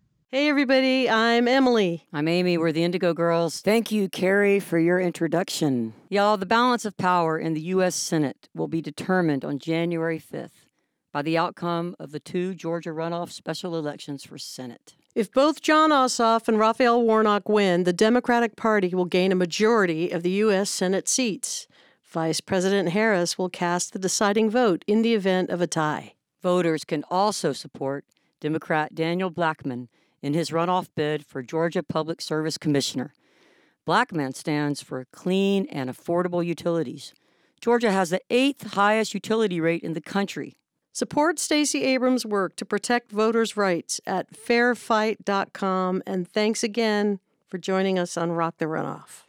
(audio captured from webcast)
03. talking with the crowd (indigo girls) (0:49)